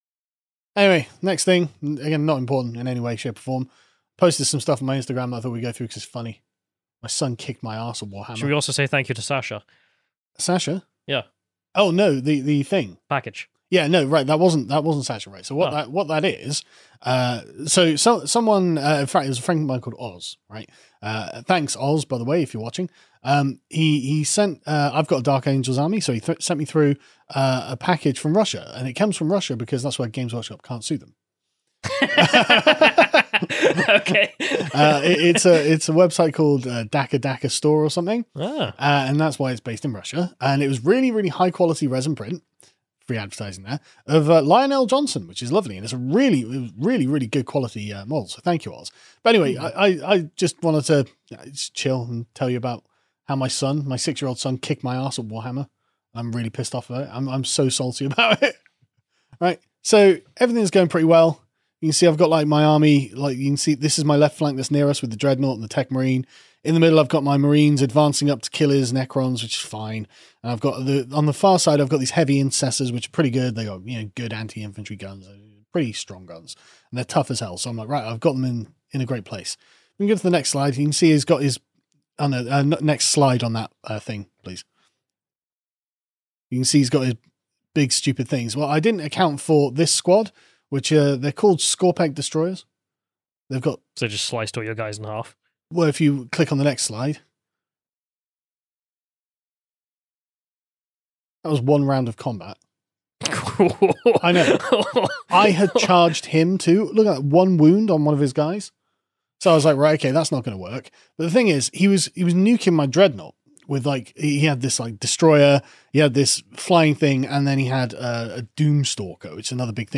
our first Live Premium Exclusive Hangout